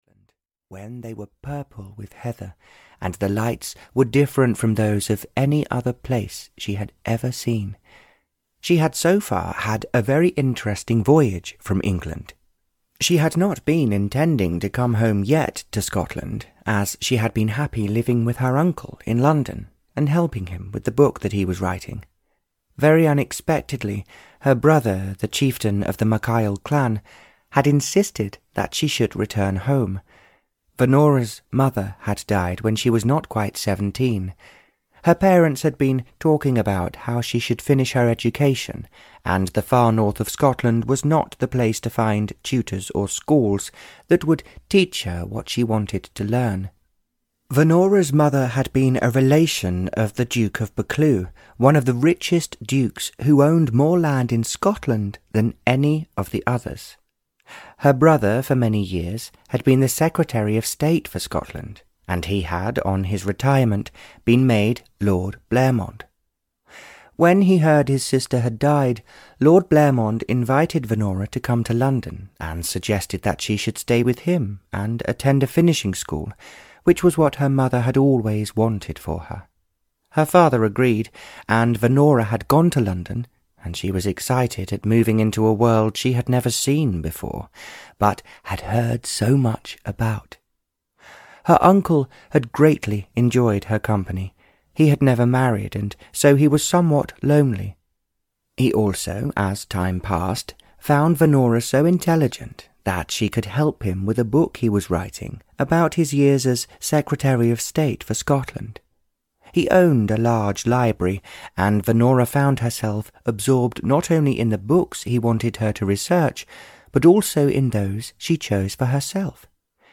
Audio knihaA Heart of Stone (Barbara Cartland’s Pink Collection 114) (EN)
Ukázka z knihy